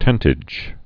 (tĕntĭj)